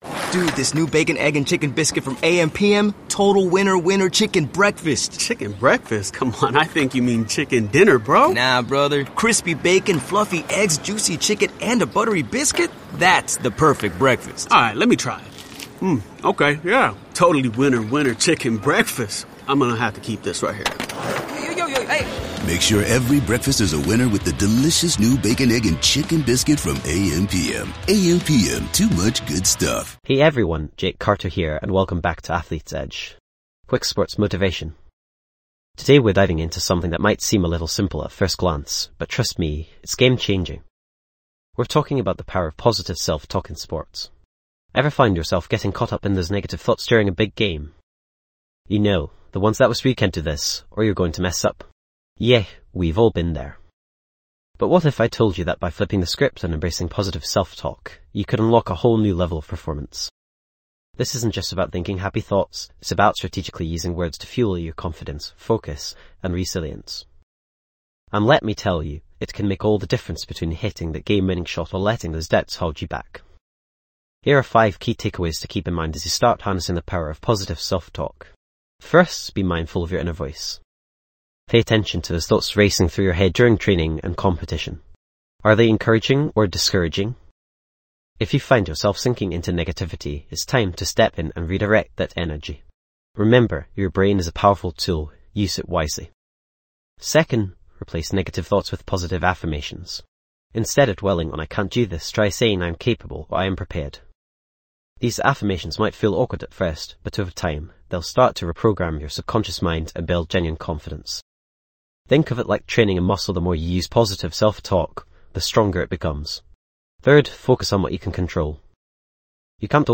Sports & Recreation Motivational Sports Talks
This podcast is created with the help of advanced AI to deliver thoughtful affirmations and positive messages just for you.